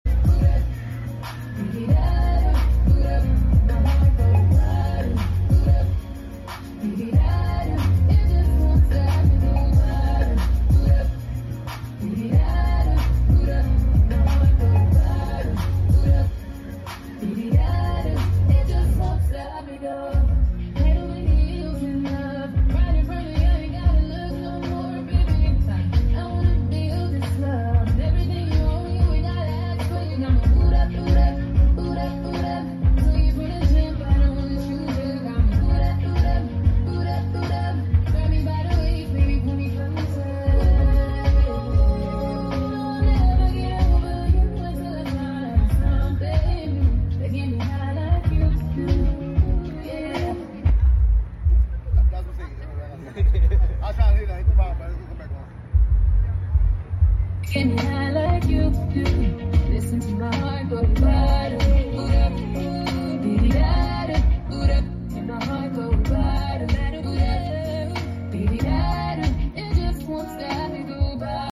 Having some fun during sound check